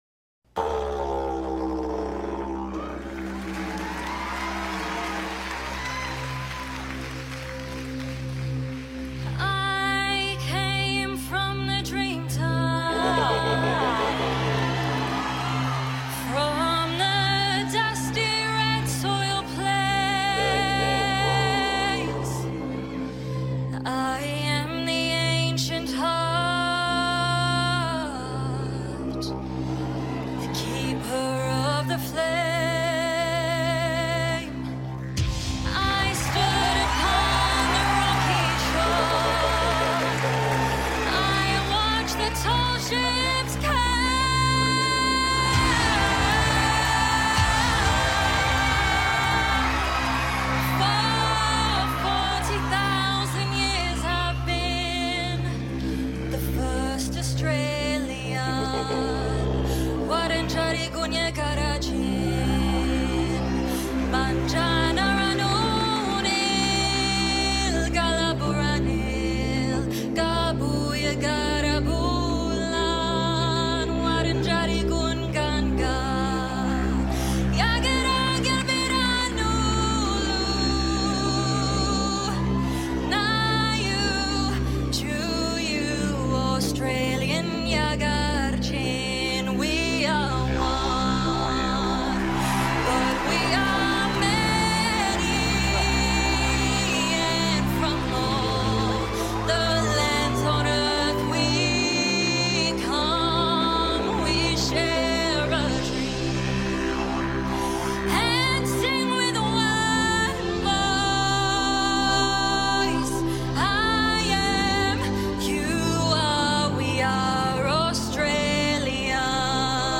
Please help share this amazing cover